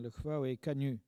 Mémoires et Patrimoines vivants - RaddO est une base de données d'archives iconographiques et sonores.
locutions vernaculaires